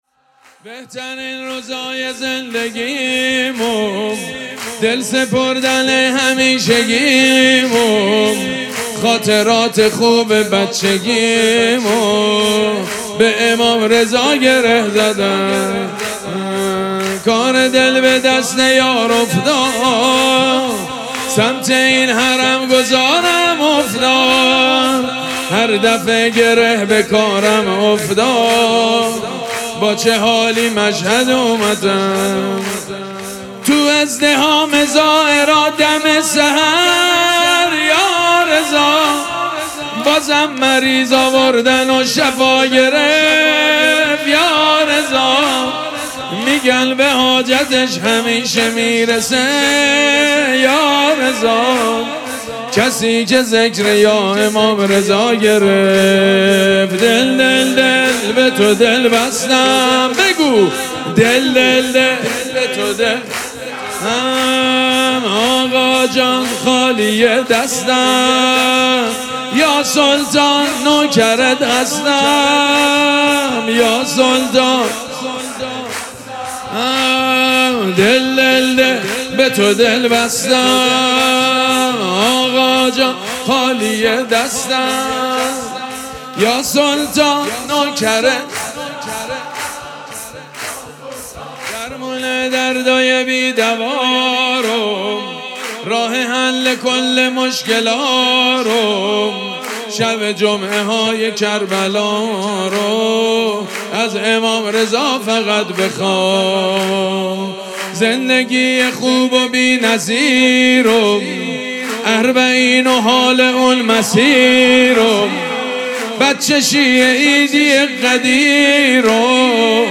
مراسم جشن ولادت امام رضا علیه‌السّلام
سرود
مداح
حاج سید مجید بنی فاطمه